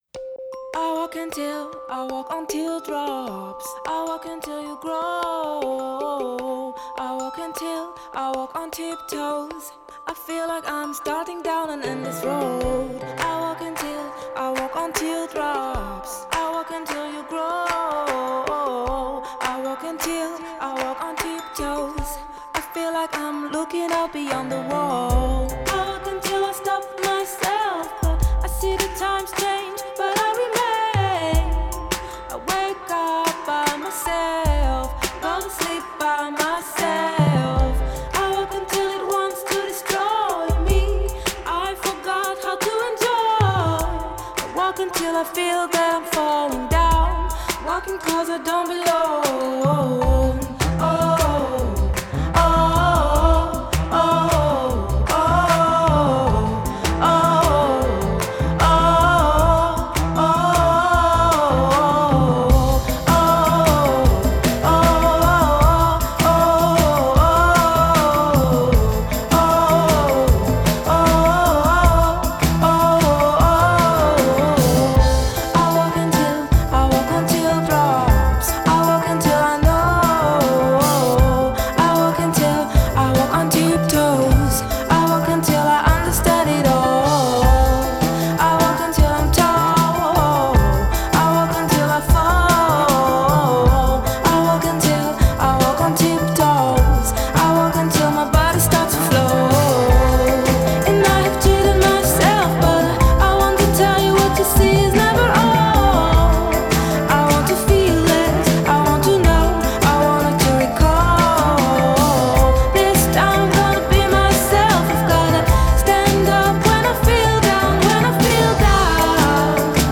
Жанр: Indie, Folk, Pop
Genre: Female vocalists, Indie, Folk, Pop